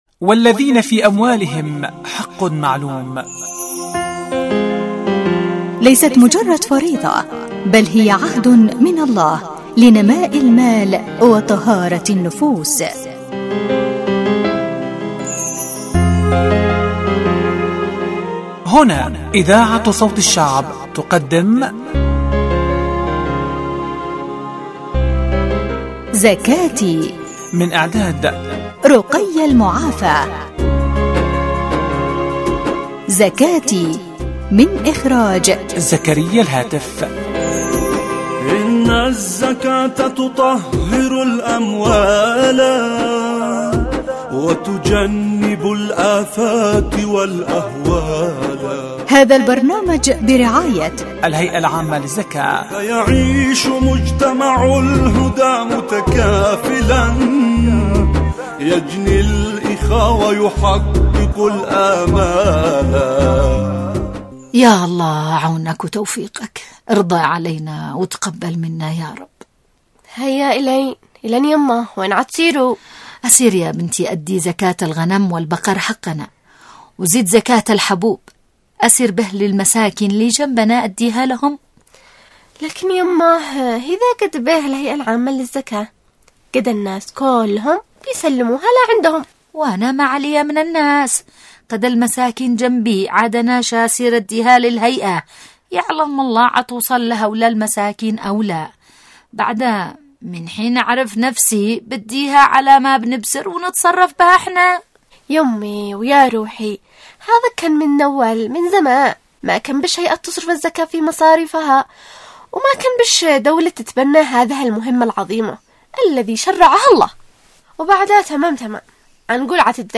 البرامج الحوارية زكاتي زكاتي ح 19